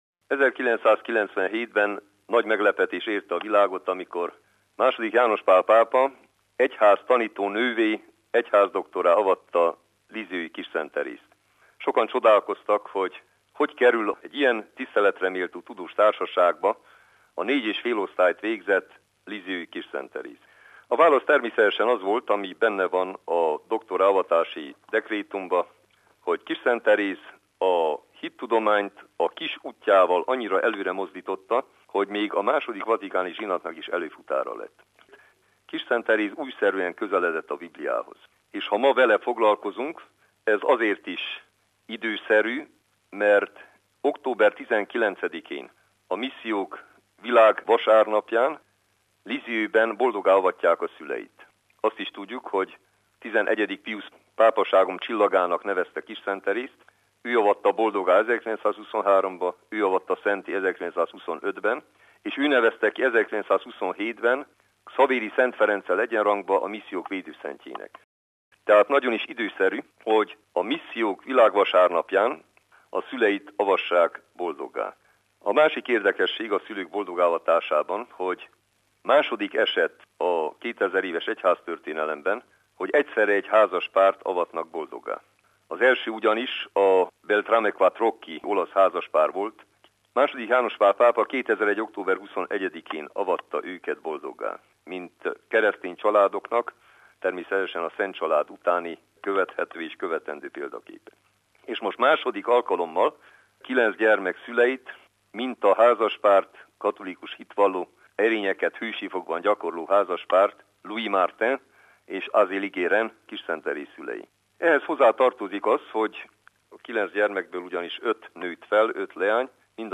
Hogyan lett egyházdoktor a kármel „kis virága”? – Jakubinyi György érsek előadása
Hétfőn Jakubinyi György, gyulafehérvári érsek „Lisieux-i Szent Teréz egyházdoktor és a Szentírás” címmel tartott előadást. A főpásztor összefoglalta tanulmányát hallgatóink számára.